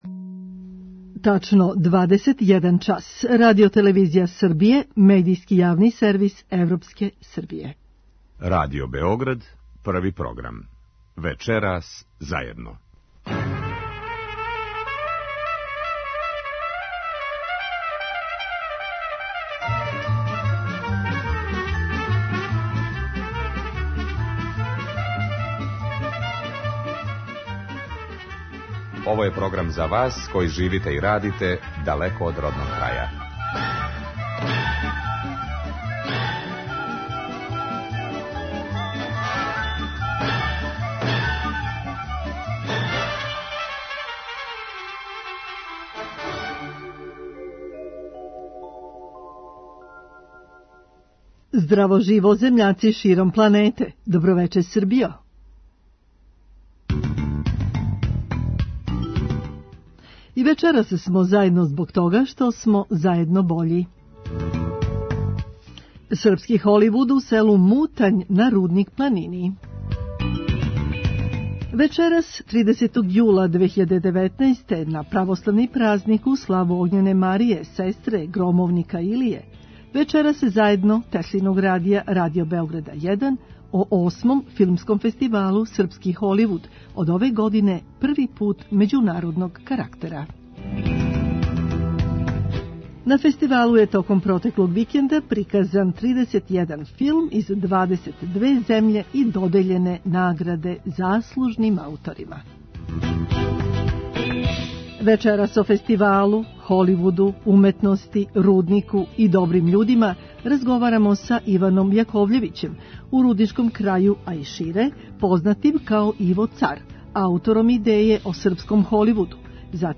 Чућете разговор